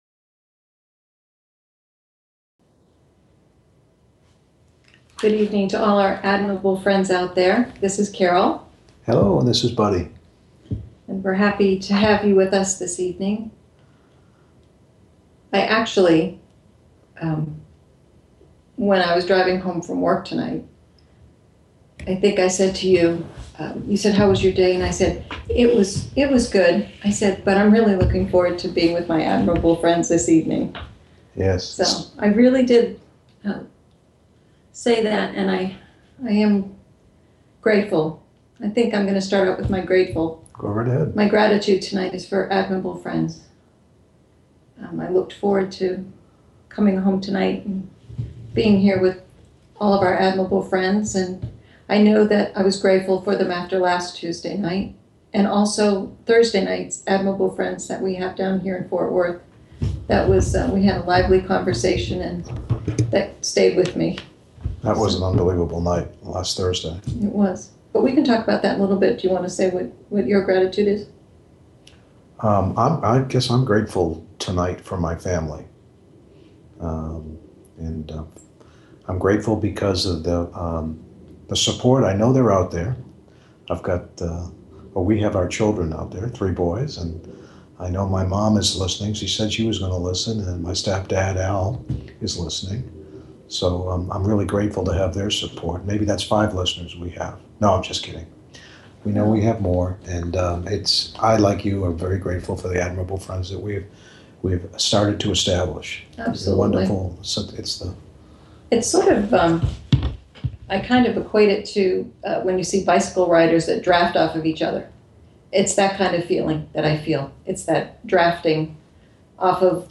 Tonight's Admirable Friends Talk Show is on AUTOPILOT AND AUTHENTICITY.